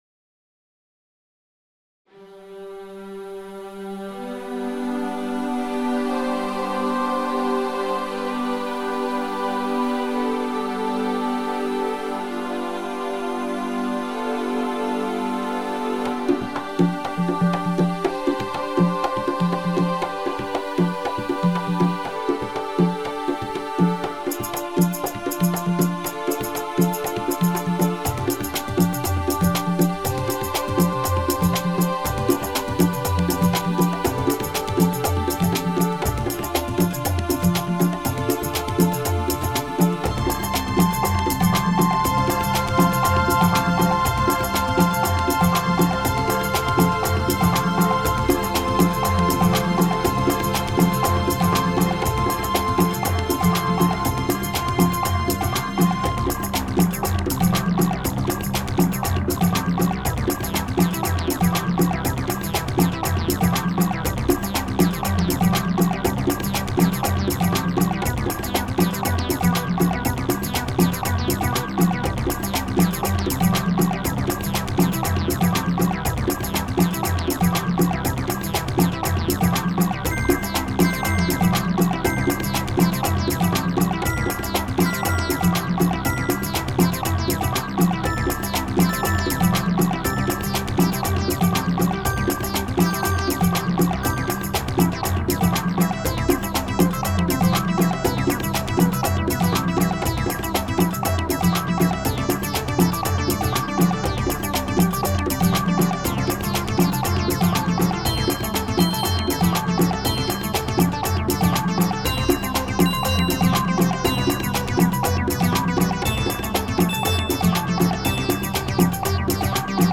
Home > Music > Electronic > Bright > Dreamy > Running